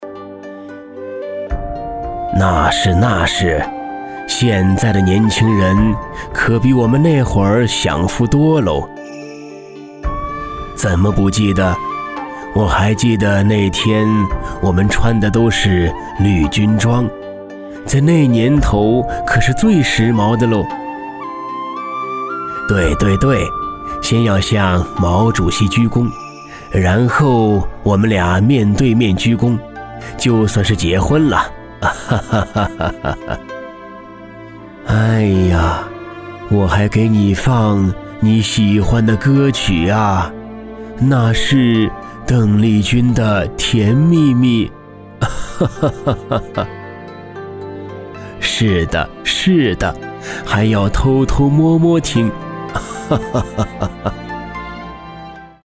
【角色】-模仿老人